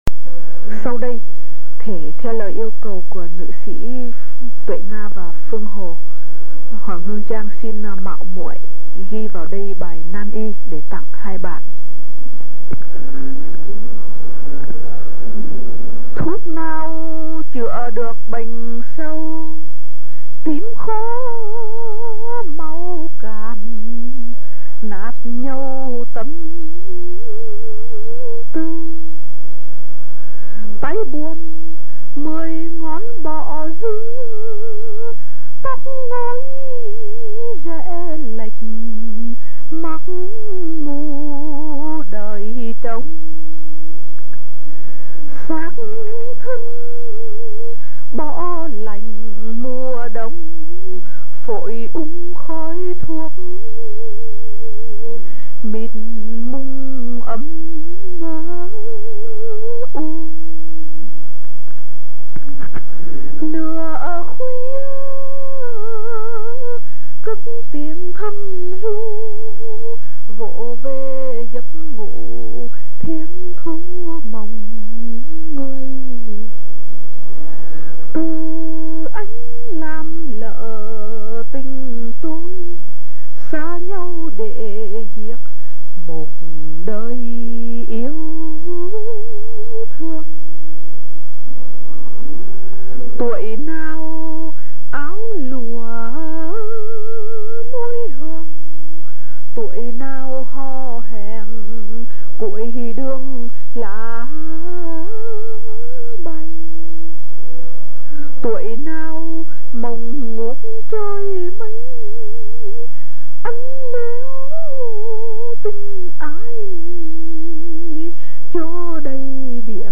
Bài thơ vô cùng dễ thương, giọng ngâm tuy không nhạc đệm nhưng âm hưởng luyến láy, ngân nga, ngọt ngào.